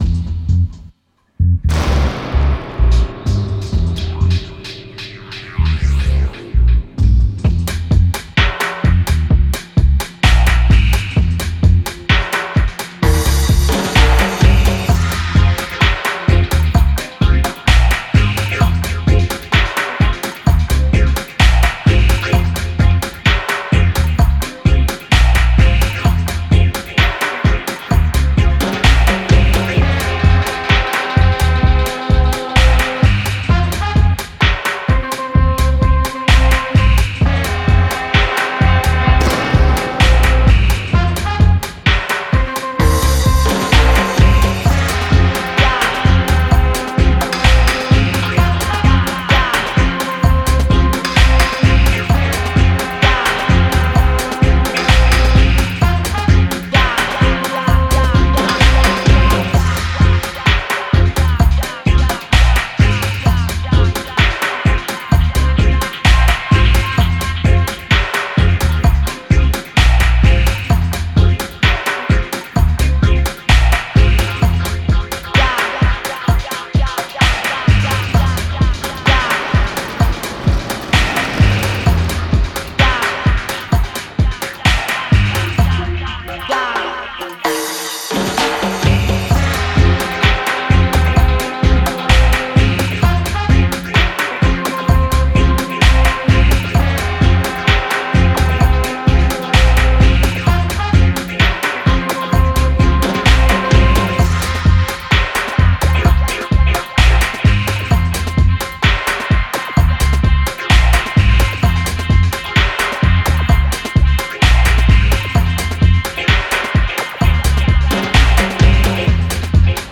Genre:Dub
デモサウンドはコチラ↓